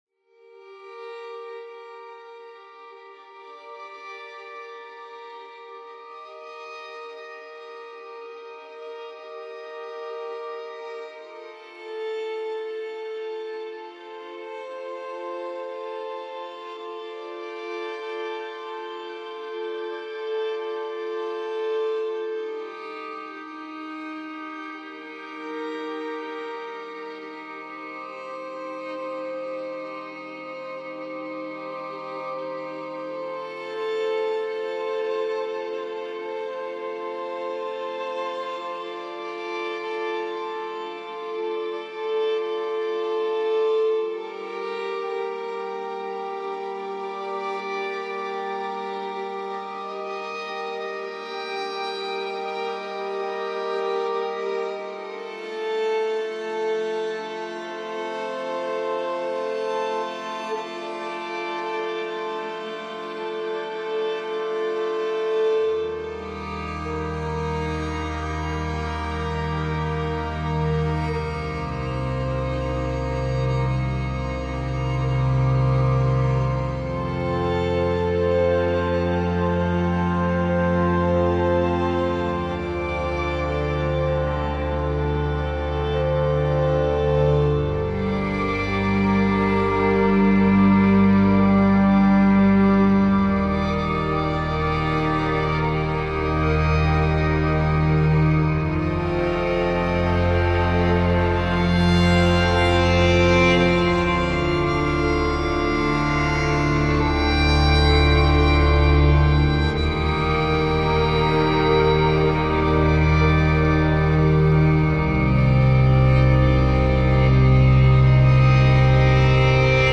Experimental violin library